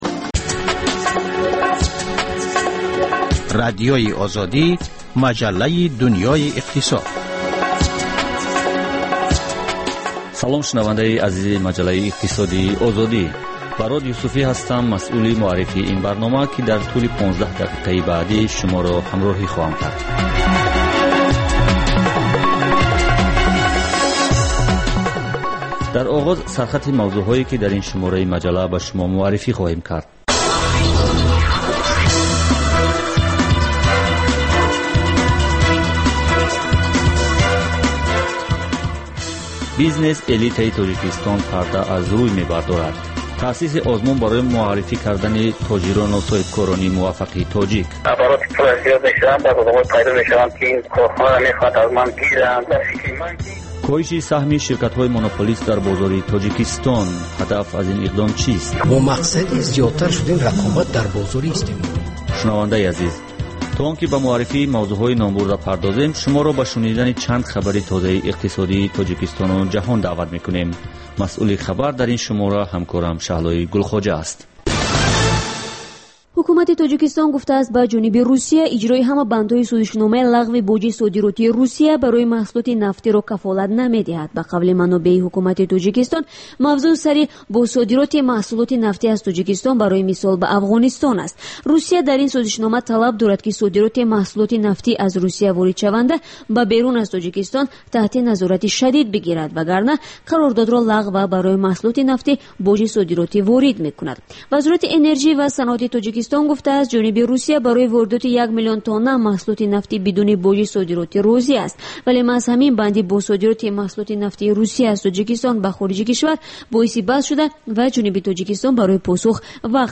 Дар маҷаллаи Дунёи иқтисод коршиносон, масъулони давлатӣ ва намояндагони созмонҳои марбутаи ғайридавлатию байналмилалӣ таҳаввулоти ахири иқтисоди кишварро баррасӣ мекунанд.